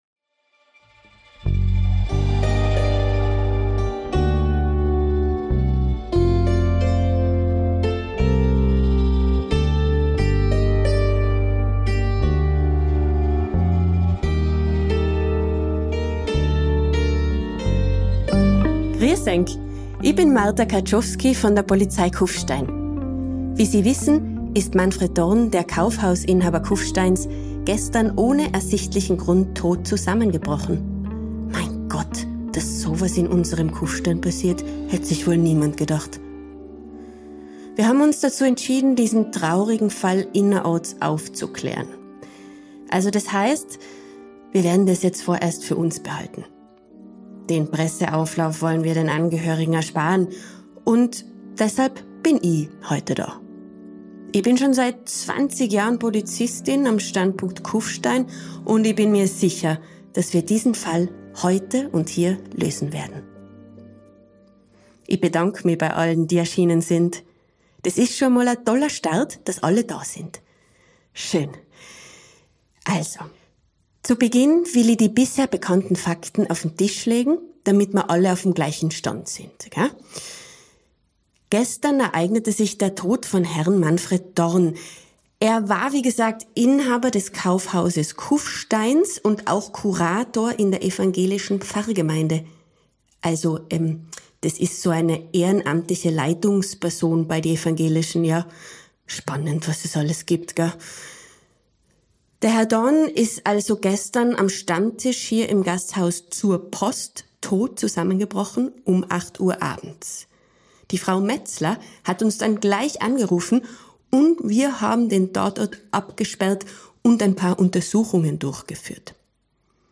Polizistin Martha Karczowksy gibt uns eine Zusammenfassung über die bisher bekannten Fakten.